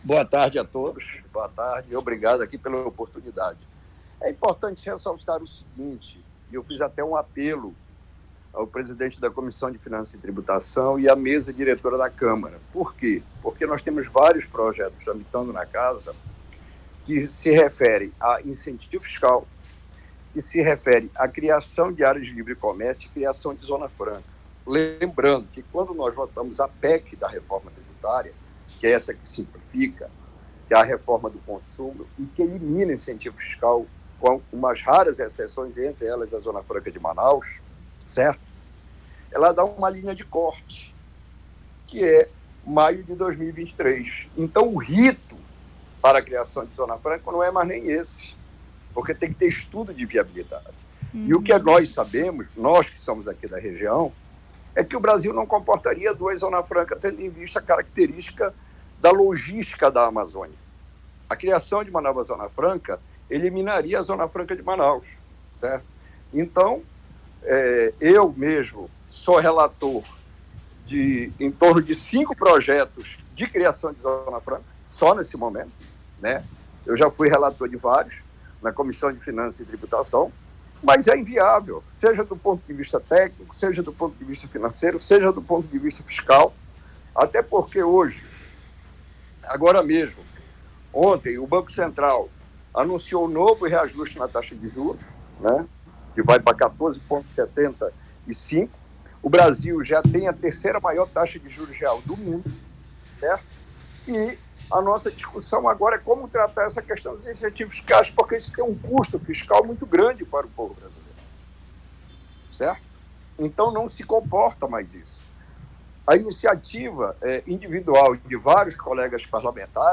Criação da Zona Franca no DF é inconstitucional, diz deputado Sidney Leite em entrevista à CBN